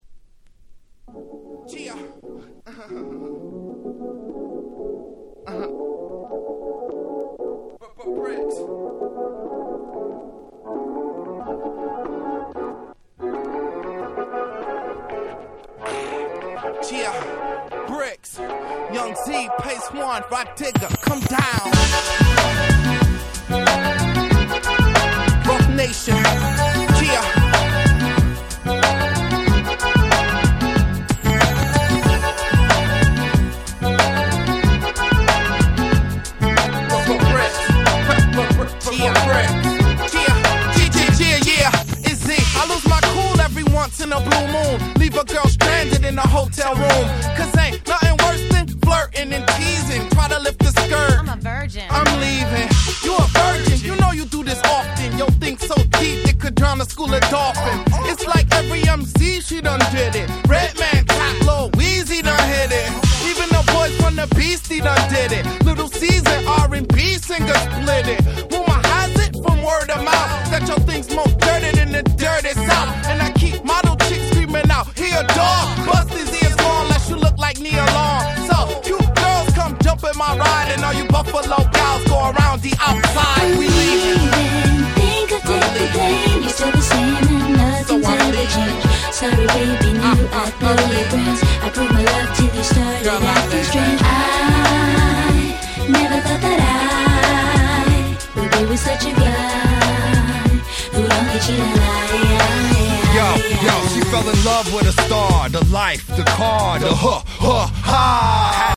02' Smash Hit Hip Hop !!
日本人受けバッチリな哀愁Beatにサビには女性Vocalも入ってくるキャッチーな1曲！！